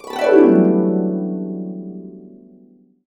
Index of /90_sSampleCDs/USB Soundscan vol.62 - Complete Harps [AKAI] 1CD/Partition D/03-GLDN140 1